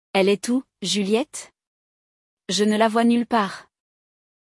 No episódio de hoje, acompanhamos uma conversa entre duas amigas em uma festa.